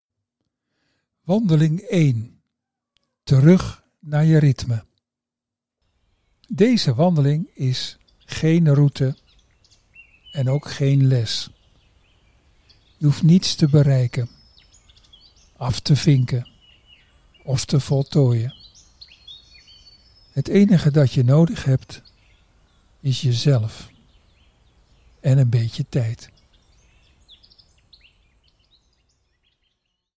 Wandelmeditatie
Je wandelt in je eigen omgeving, op jouw moment, terwijl je luistert naar meditatieve begeleiding die je helpt verstillen, voelen, en terugkeren naar wat écht voor jou belangrijk is.
• Zes begeleide audiowandelingen
Geluidsfragment wandeling